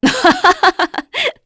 Glenwys-Vox_Happy3_kr.wav